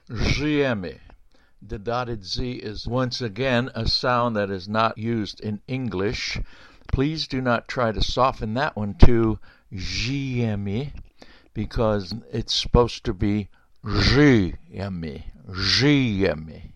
Polish Words -- Baritone Voice
żyjemy (zhee - YEH - mih)
NOTE: The "dotted" /Ż/ is a different letter from the "unmodified" /Z/ and is pronounced exactly like the French [J] in "jardin".